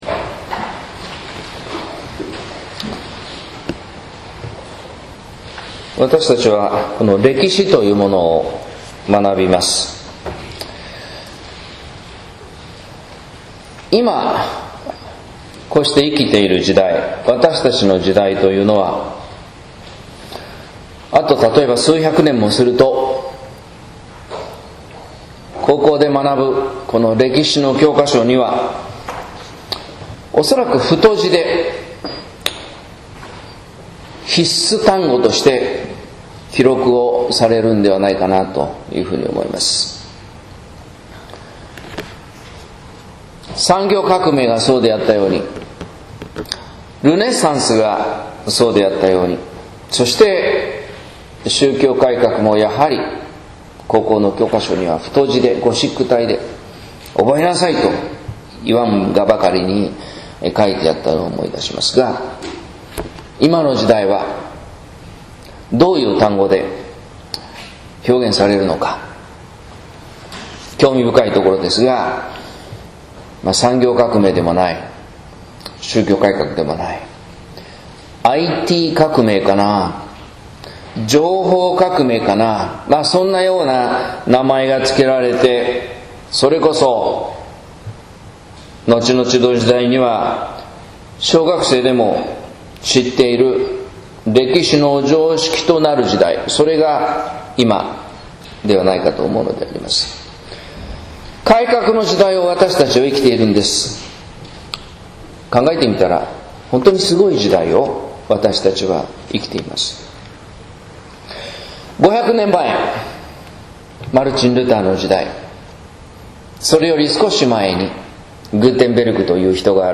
説教「伝統を改革に学ぶ」（音声版） | 日本福音ルーテル市ヶ谷教会